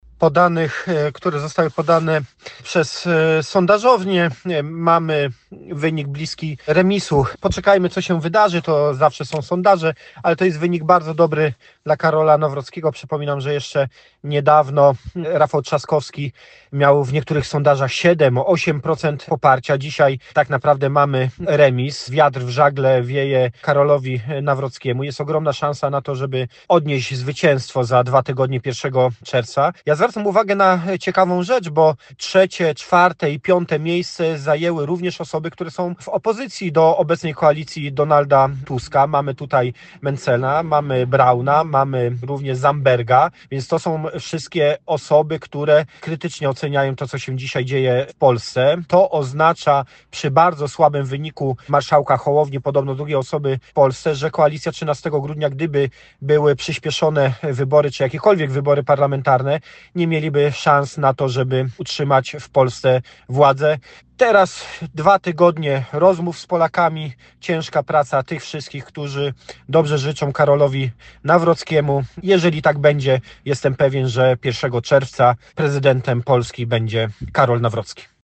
-To bardzo dobry wynik Karola Nawrockiego – tak komentował z kolei Paweł Hreniak, poseł na Sejm z PiS.
Pawel-Hreniak.mp3